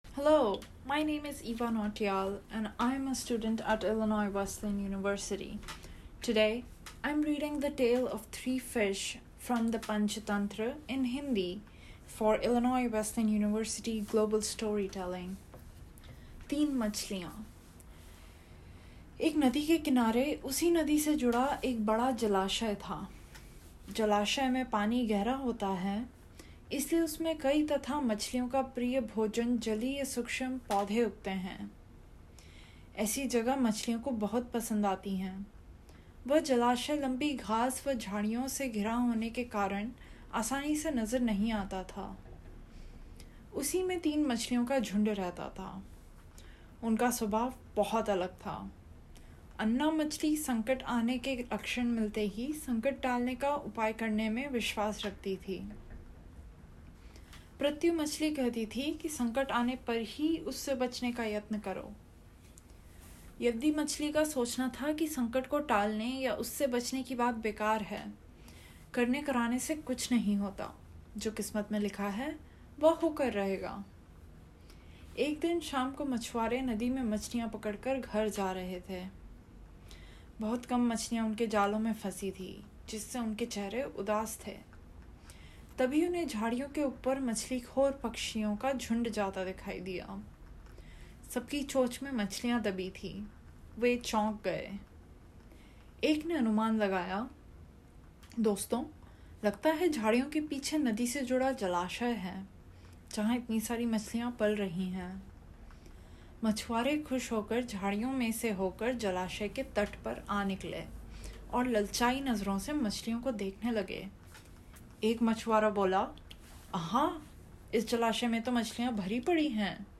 Oral History Item Type Metadata